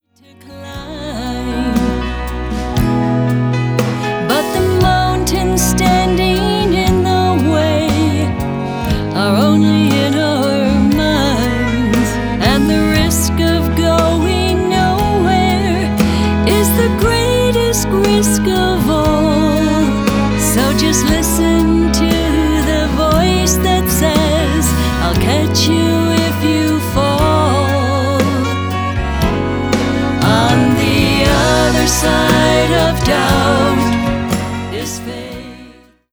Lead Vocal
Piano / Organ
Drums / Timpani
Cello
Violin
Viola
Back ground vocals